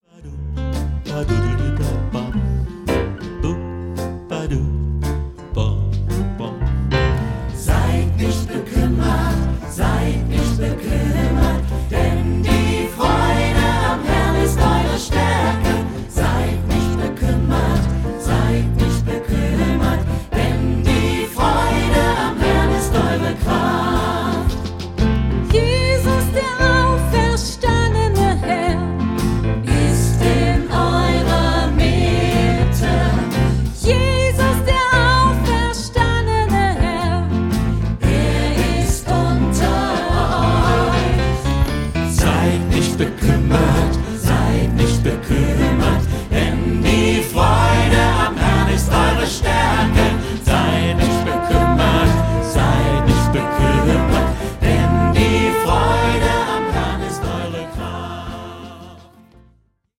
Christliche Evergreens
Liedermacher